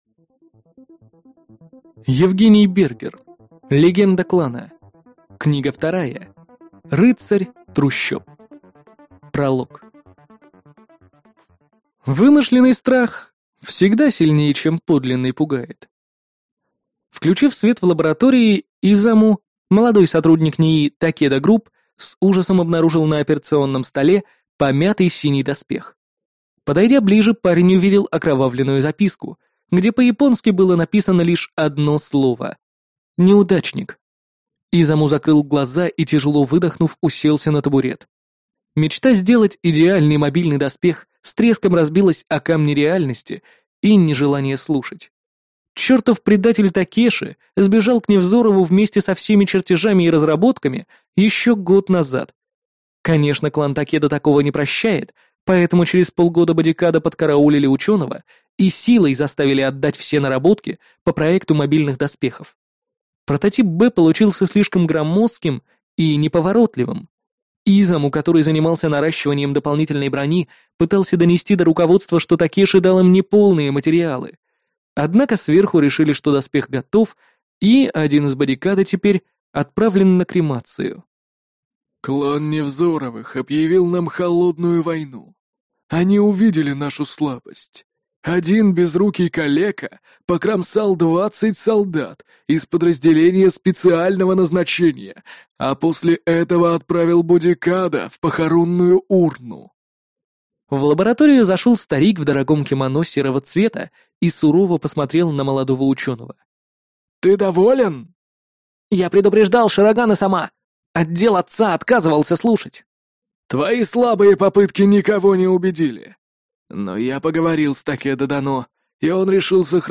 Аудиокнига Рыцарь трущоб | Библиотека аудиокниг